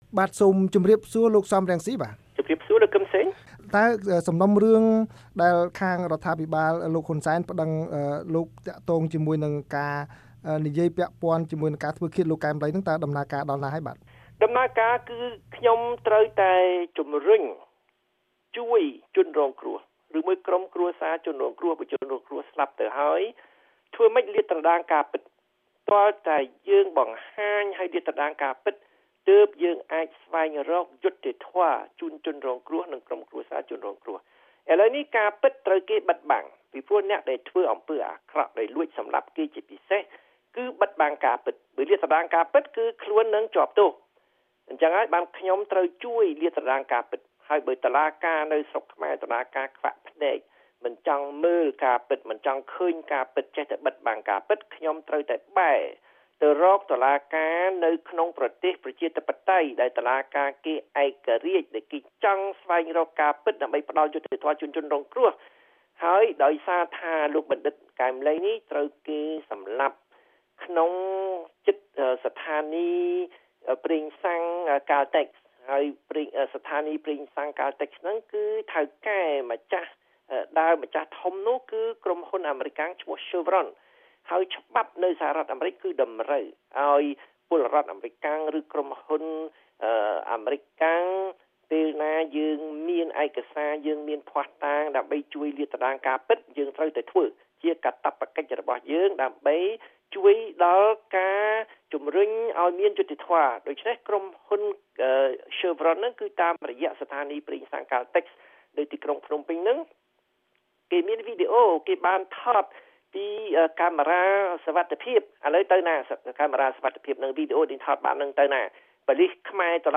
បទសម្ភាសន៍ VOA៖ លោកសម រង្ស៊ីប្តេជ្ញាស្វែងរកយុត្តិធម៌ដល់លោកកែម ឡី